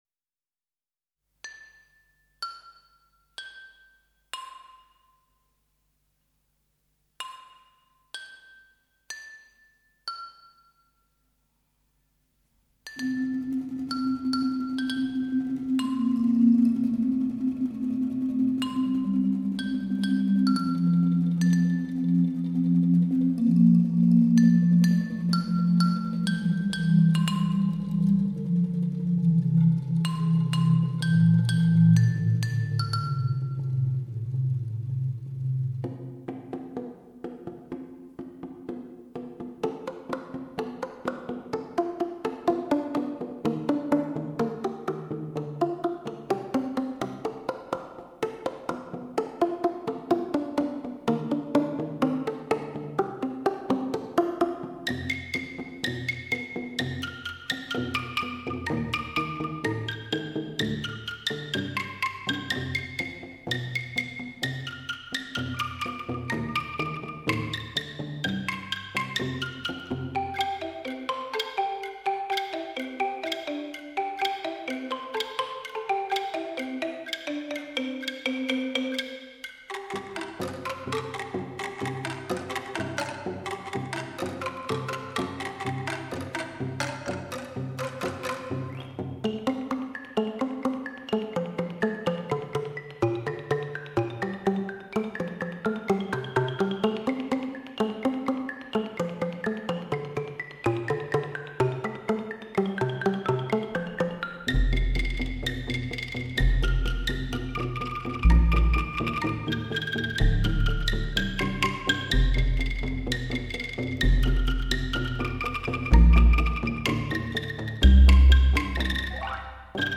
极具发烧元素绿色环保音乐  天碟级录音
九位竹乐演奏名家演奏
穿越自然质朴空灵的音域，透过竹林曼妙的声响，领略古老而清新的竹乐器神韵，聆听异域色彩和独特的原生态精粹。
九位竹乐演奏名家远赴北京中央电视台，于全国最先进的录音室中灌录本碟。